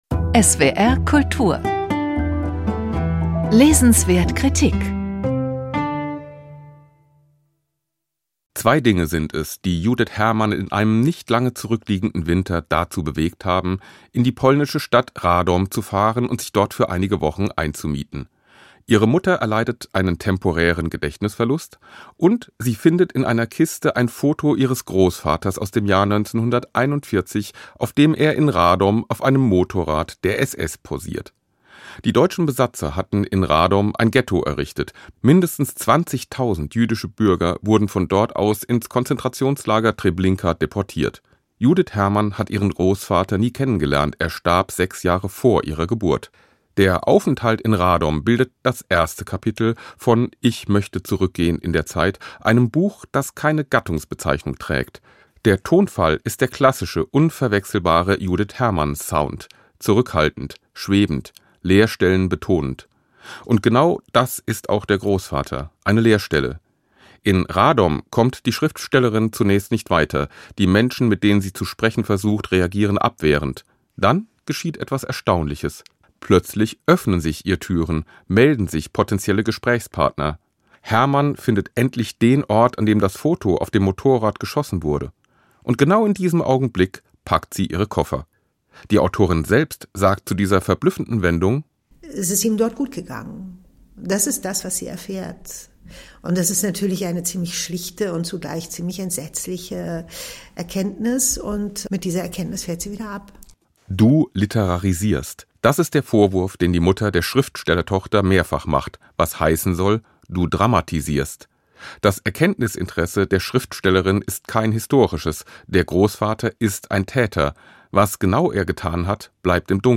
Rezension